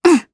Gremory-Vox_Attack2_jp.wav